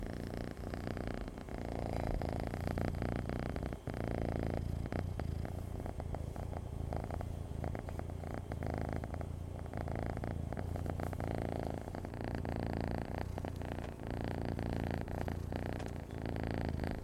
Звуки мурчания и урчания кошек
Автомобильное мурлыкание кота
avtomobilnoe_murlikanie_kota_a8h.mp3